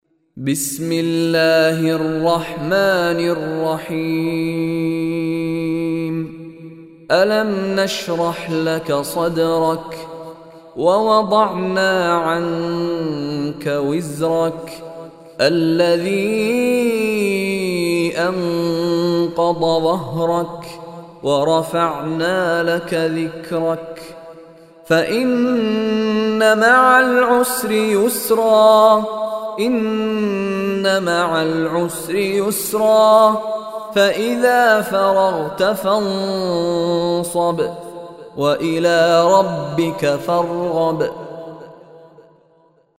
Surah Inshirah MP3 Recitation by Mishary Rashid
Surah Inshirah is 94 Surah or chapter of Holy Quran. Listen online and download mp3 tilawat / Recitation of Surah Inshirah in the beautiful voice of Sheikh Mishary Rashid Alafasy.